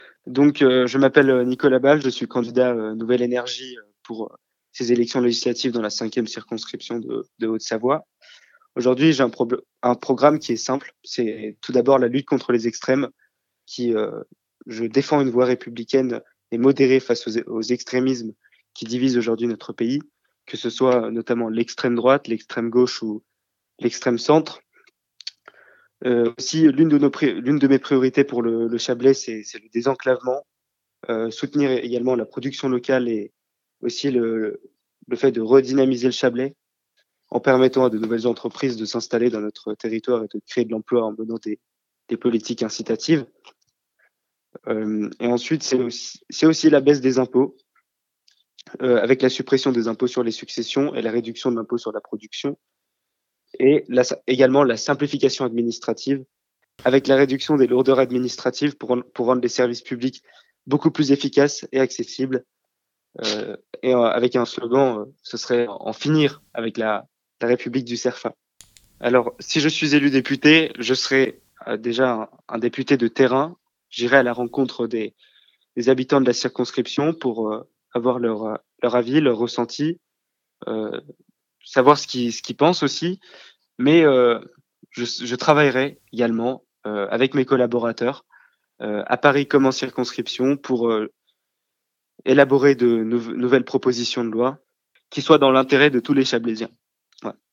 Gros plan sur les candidats du Chablais (interviews)
Voici les interviews des 8 candidats de cette 5ème circonscription de Haute-Savoie (par ordre du tirage officiel de la Préfecture)